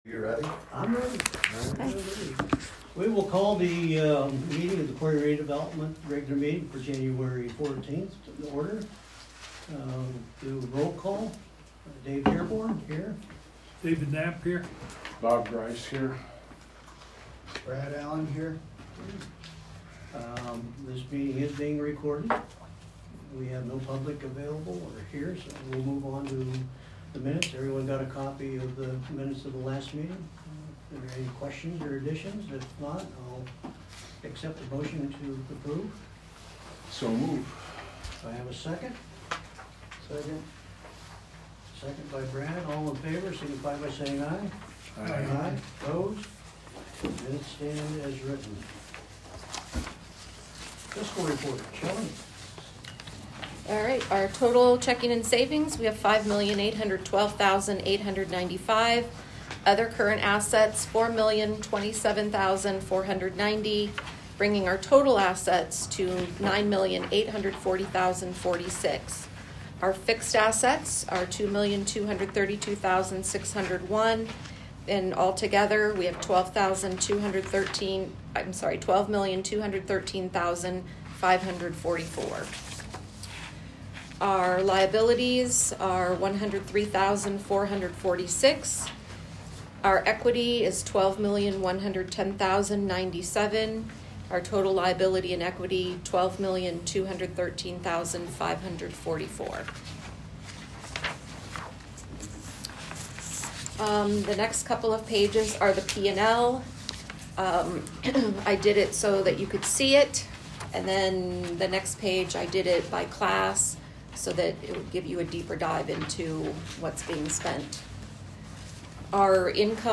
Meeting Audio